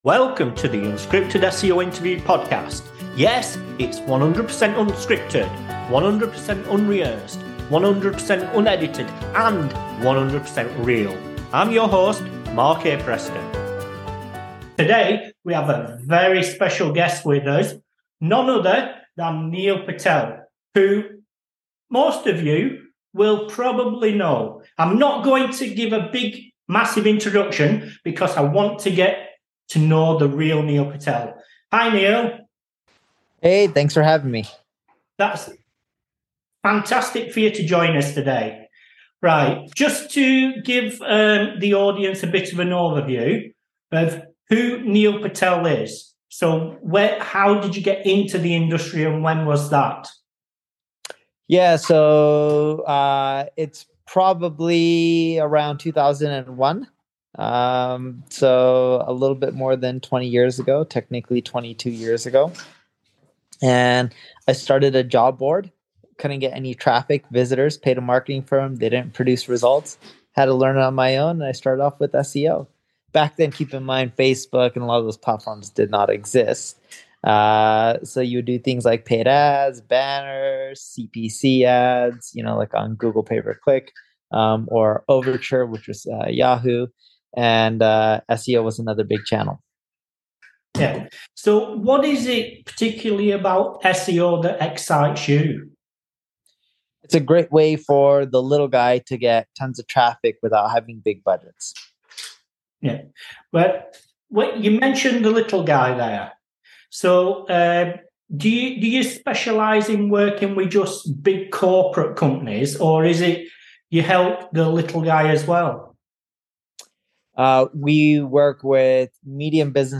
Neil is the co-founder of NP Digital, a 9-figure agency. Neil Patel came on The Unscripted SEO Interview Podcast as a guest so we can all get to know who the real Neil Patel is.